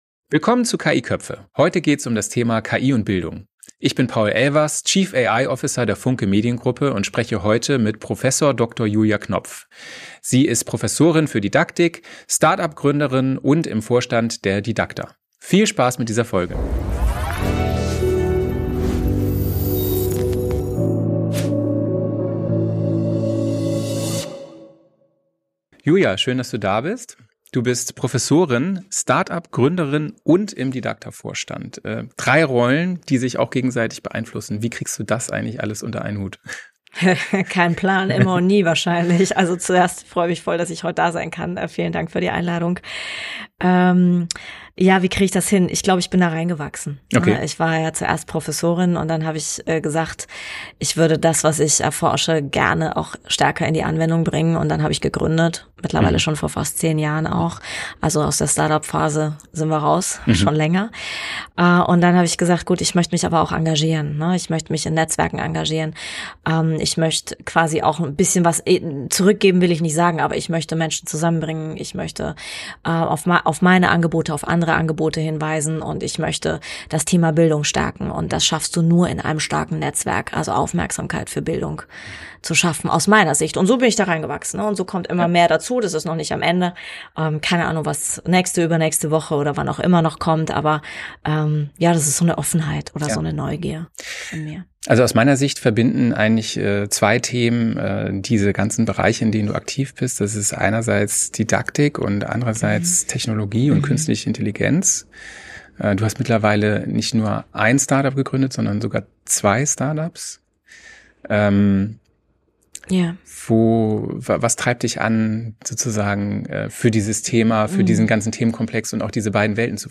Ein Praxisgespräch über Didaktik, neue Prüfungsformen und den echten Einsatz im Klassenraum